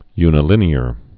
(ynĭ-lĭnē-ər)